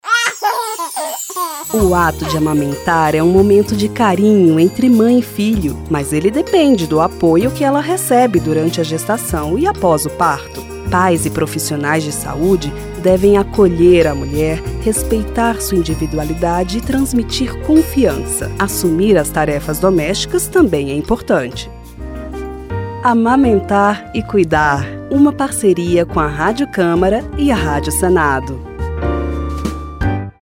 São cinco spots de 30 segundos cada.